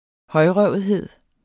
Udtale [ ˈhʌjˌʁɶwˀəðˌheðˀ ]